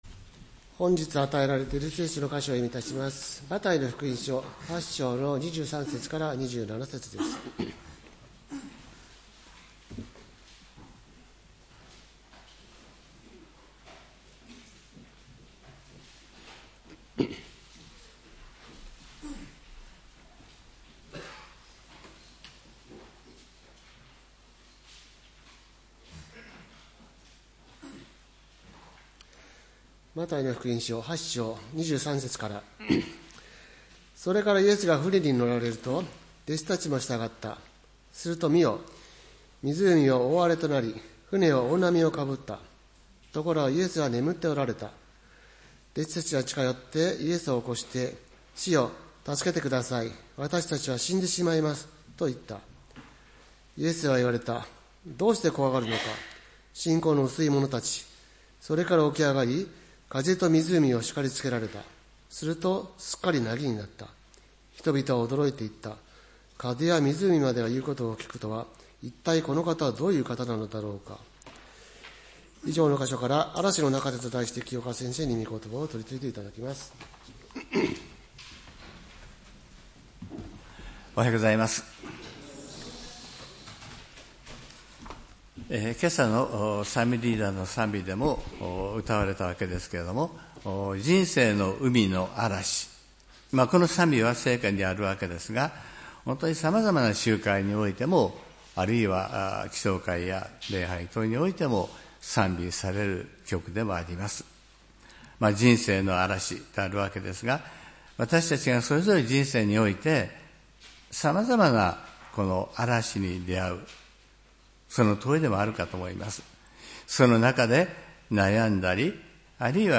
礼拝メッセージ「嵐の中で」（10月12日）